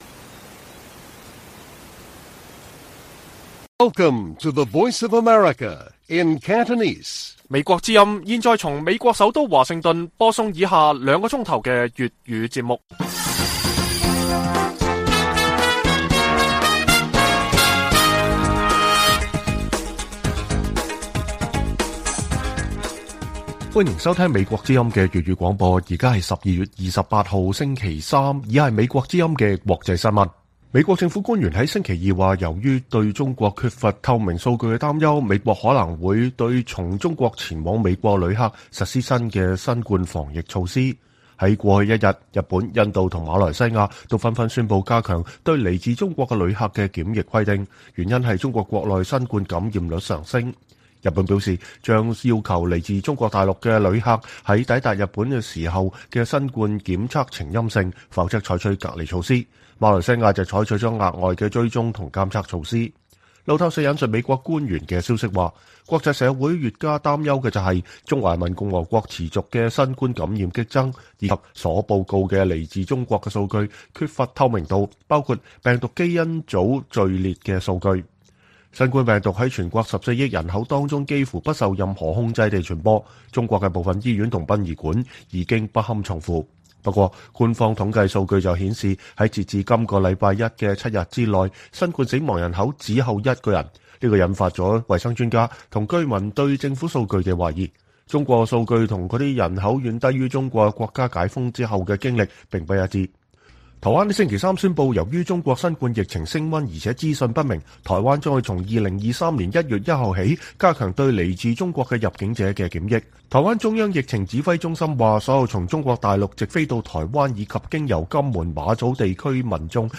粵語新聞 晚上9-10點: 美國政府質疑中國疫情數據考慮限制中國旅客入境